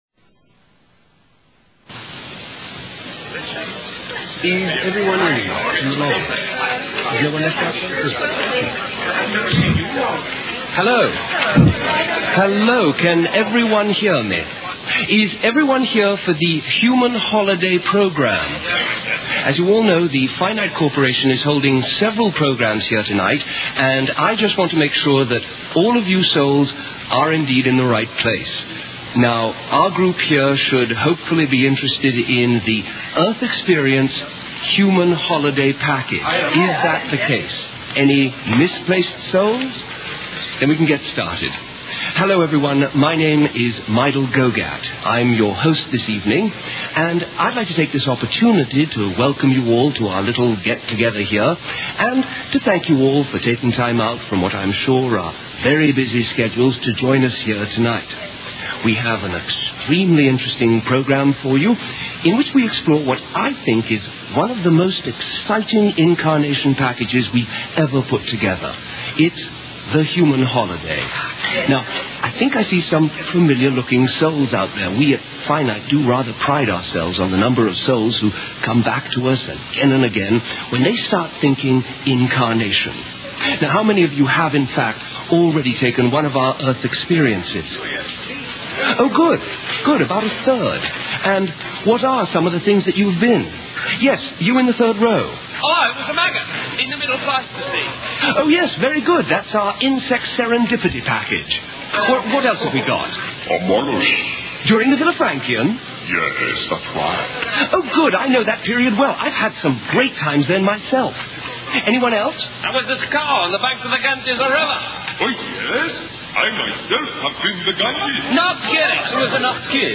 Fun Filled British Radio Play - mp3 - Part 1